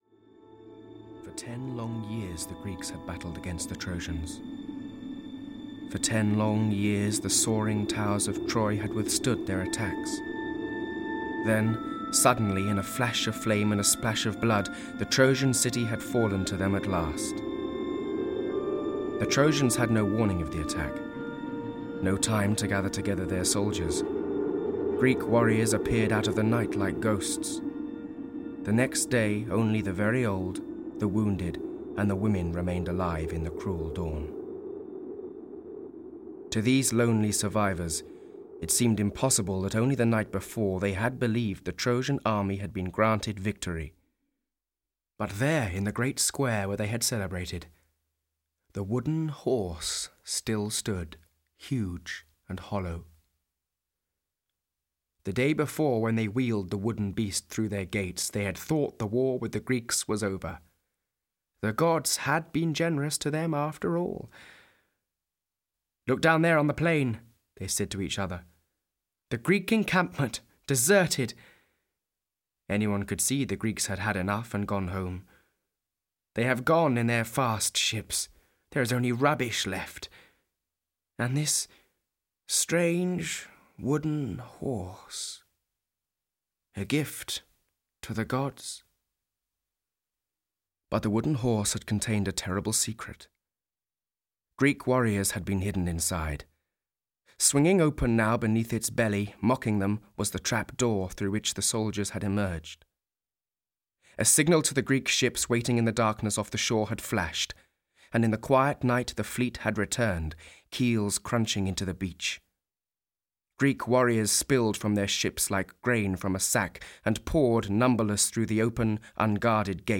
The Adventures of Odysseus (EN) audiokniha
Ukázka z knihy